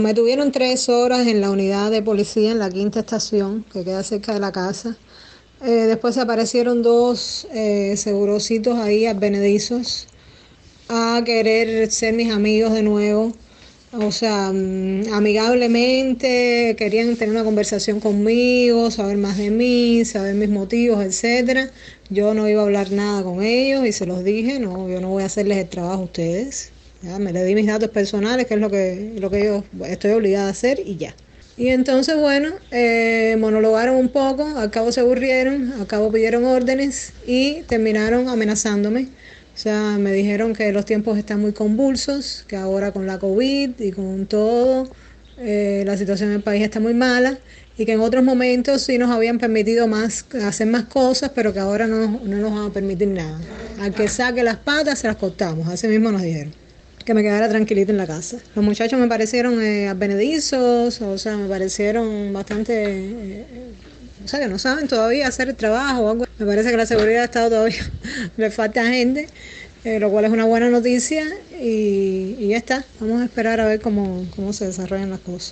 Testimonio